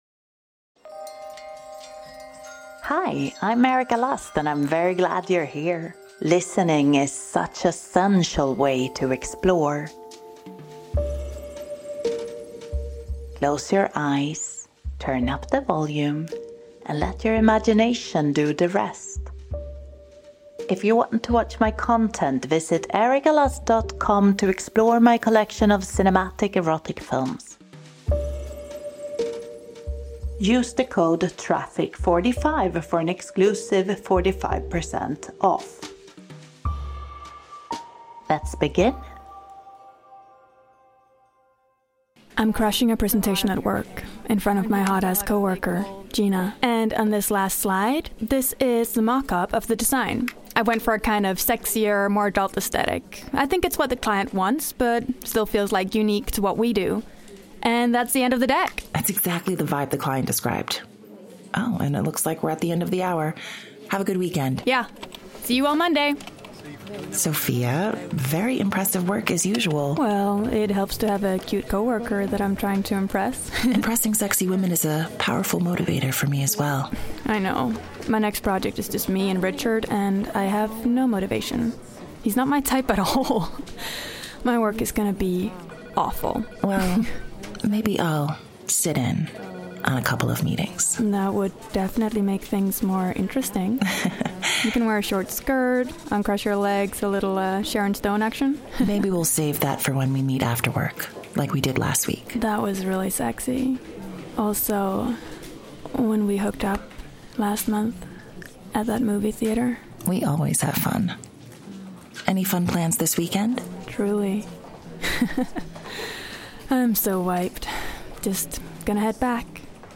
1 Guided Breathwork Meditation for Deep Relief, Peace, & Grounding 18:08
Beginning with box breathing (4-4-4-4 breath) to steady the body and mind, and then easing into 4-7-8 breathing, allowing each inhale and exhale to grow slower, deeper, and more nourishing. Each technique begins with several guided counted rounds, fo…